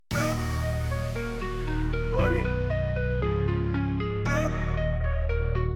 Rap，押韵